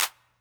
Not My Fault Clap.wav